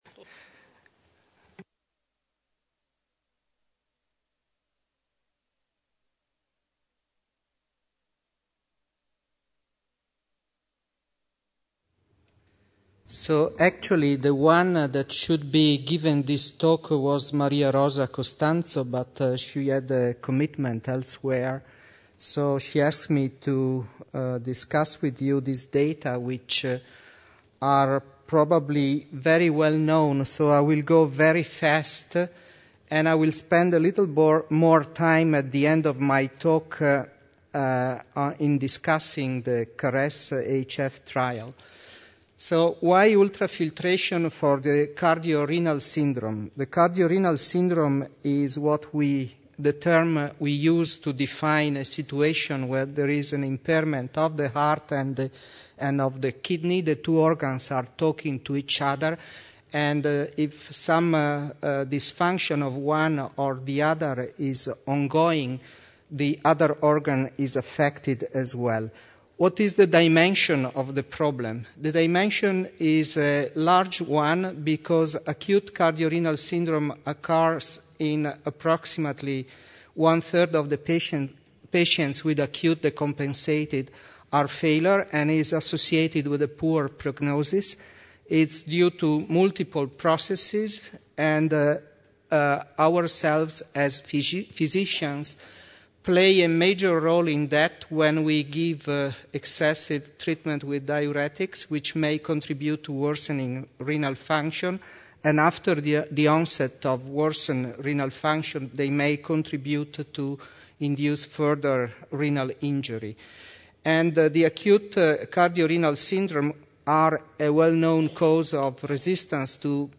Cardiovascular Clinical Trialists (CVCT) Forum – Paris 2012 - Debate Session 5 : Ultrafiltration for acute cardiorenal syndrome in heart failure